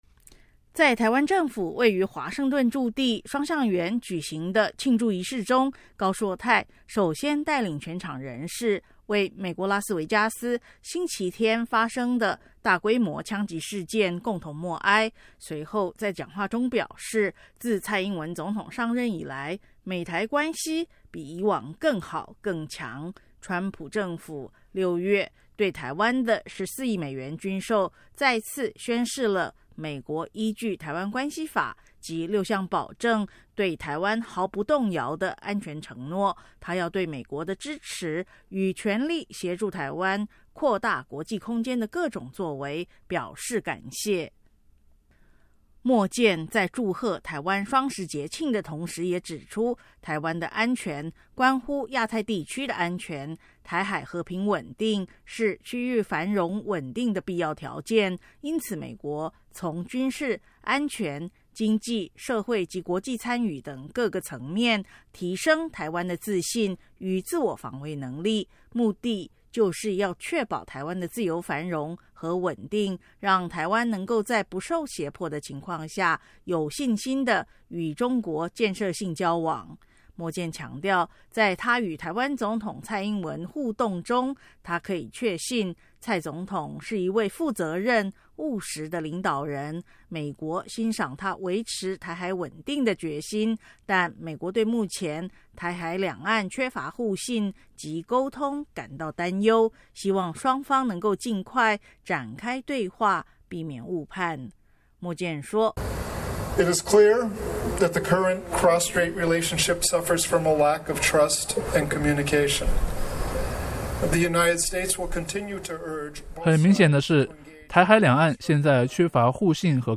台湾驻美代表处在双橡园举行双十节庆祝活动 宾客举杯祝贺
台湾驻美代表高硕泰致词